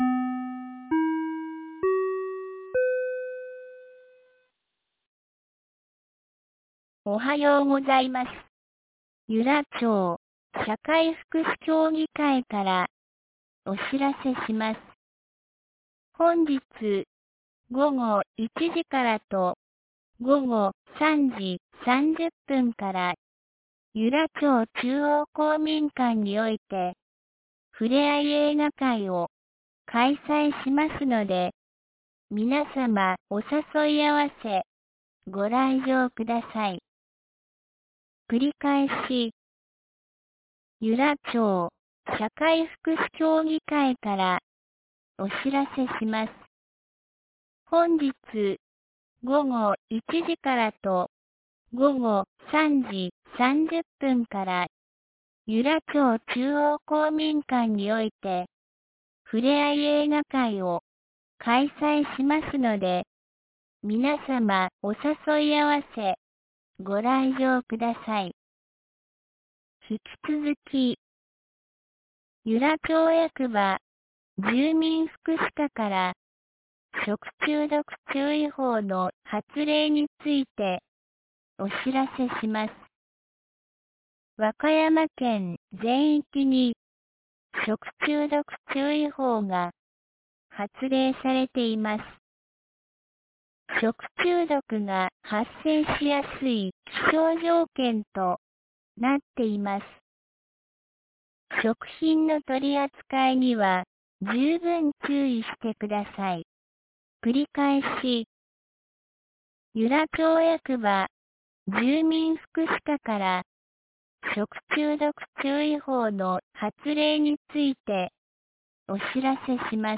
2019年08月10日 07時52分に、由良町より全地区へ放送がありました。
放送音声